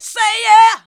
SAY YEAH 2.wav